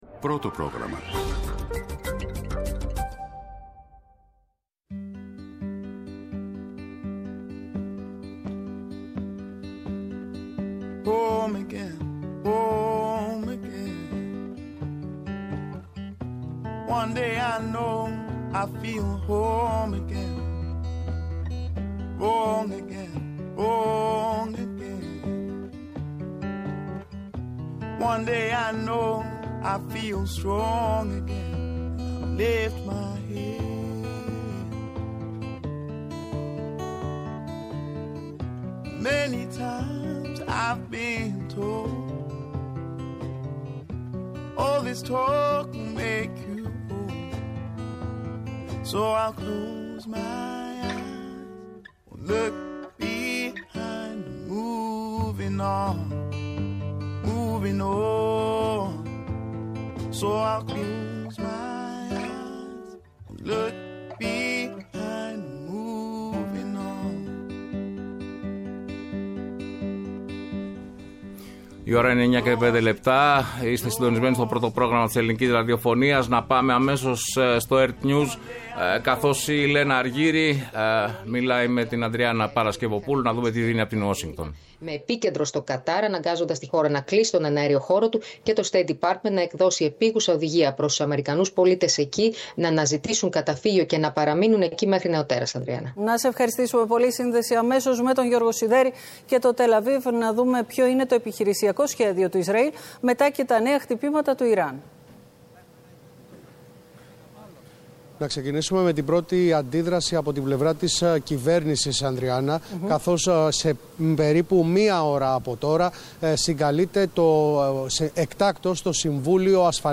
Εκτακτη ενημερωτική εκπομπή